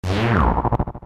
a shadow pendant deltarune Meme Sound Effect